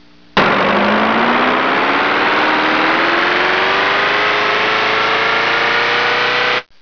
Takeoff
Takeoff.wav